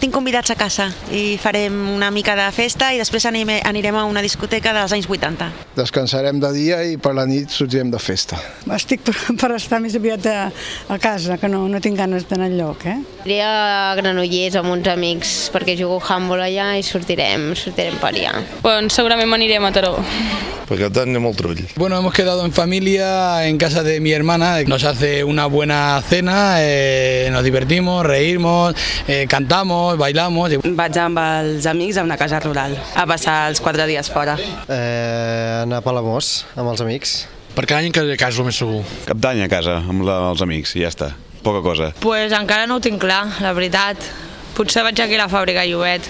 Hem sortit al carrer i això és el que ens han explicat.